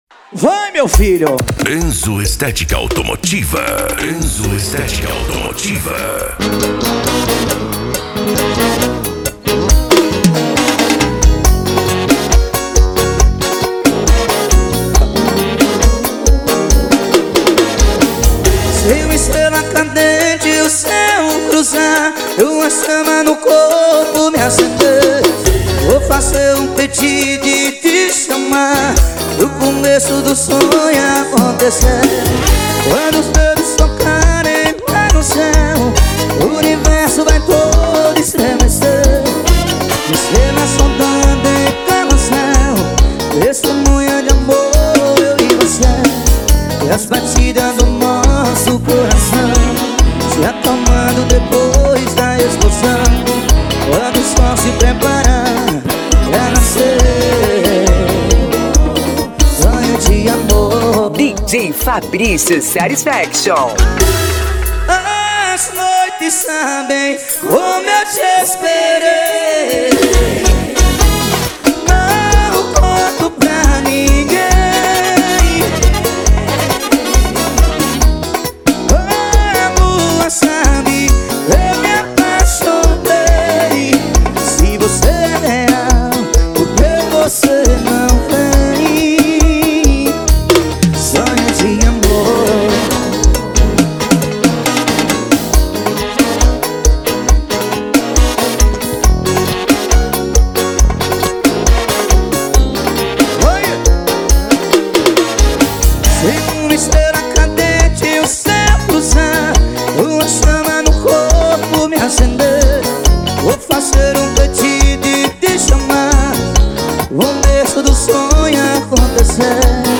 SERTANEJO
Sertanejo Raiz